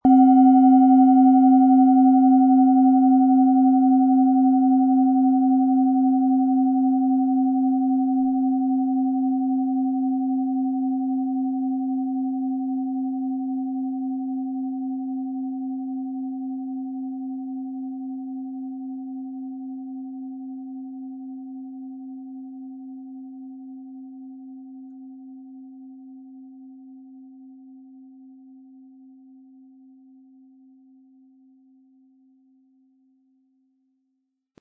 Planetenschale® Wohlige Energie im Brustbereich & Lebensenergie fördernd mit DNA-Ton, Ø 13,7 cm inkl. Klöppel
Im Sound-Player - Jetzt reinhören können Sie den Original-Ton genau dieser Schale anhören.
SchalenformBihar
HerstellungIn Handarbeit getrieben
MaterialBronze